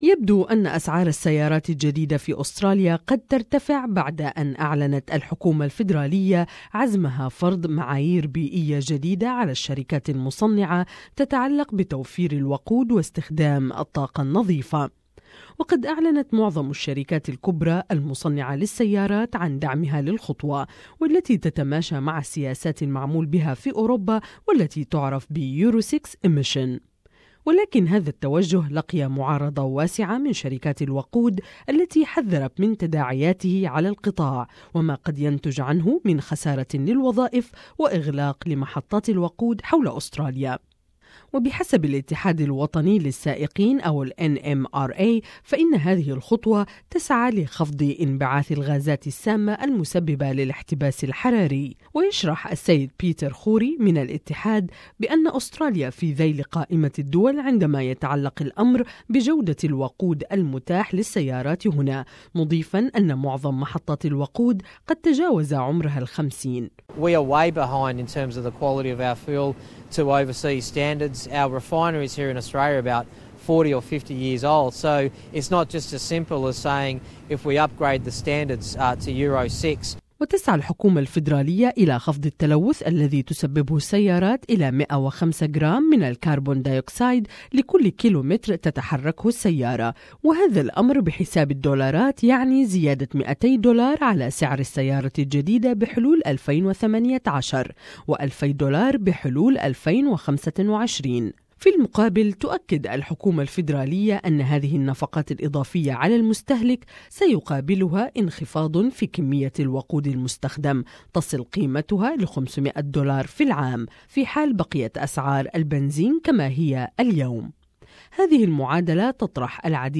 The cost of new cars could be going up as the Federal Government looks to set a compulsory clean-fuel standard. Car-makers are in agreement with the move, but fuel companies say it could lead to job losses and refinery closures. More in this report